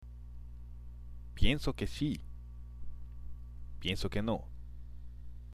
（ピエンソ　ケ　シ）（ピエンソ　ケ　ノ）